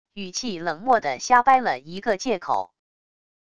语气冷漠的瞎掰了一个借口wav音频